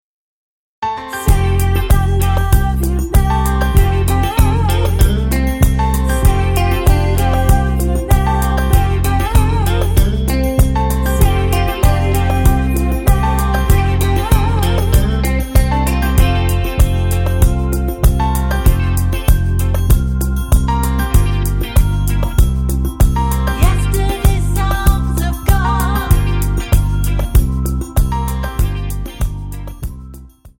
Tonart:F mit Chor
Die besten Playbacks Instrumentals und Karaoke Versionen .